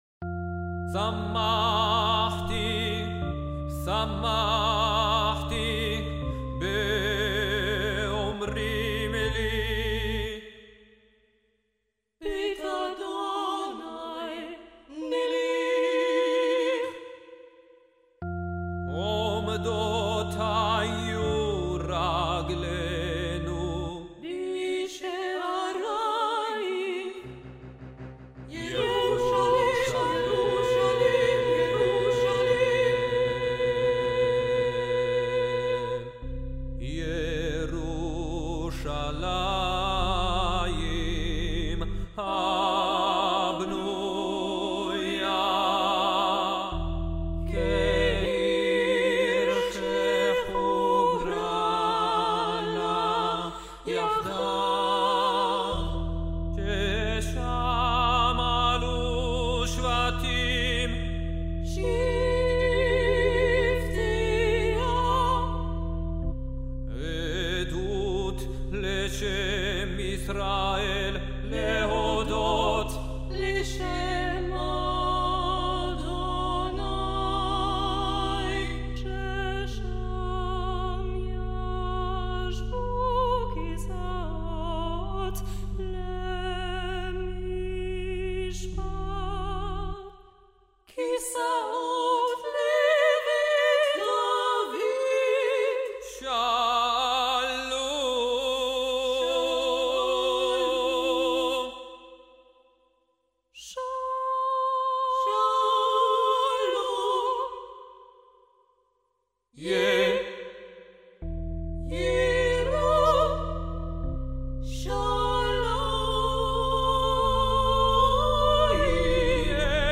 Music wonderful and Lyrics are like a wonderful chant
I particularly like the oriental factor in the music